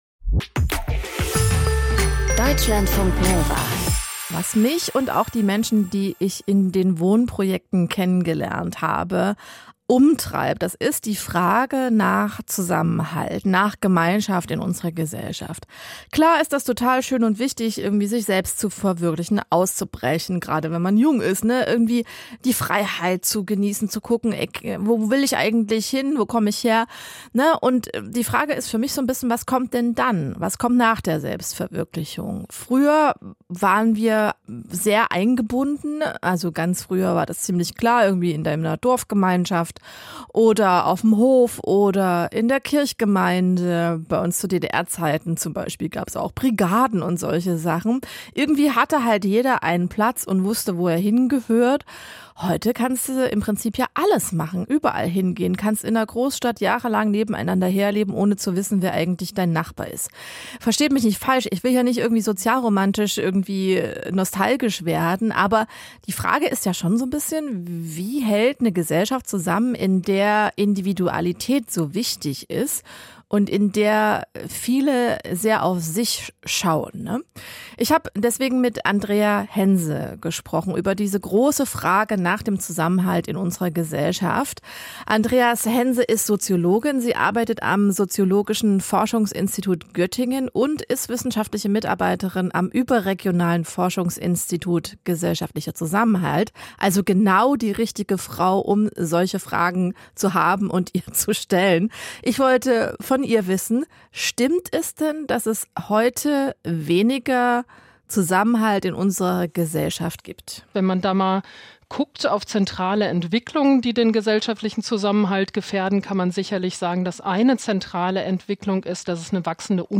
Der Wortwechsel ist das Diskussionsforum bei Deutschlandfunk Kultur – mit interessanten... Mehr anzeigen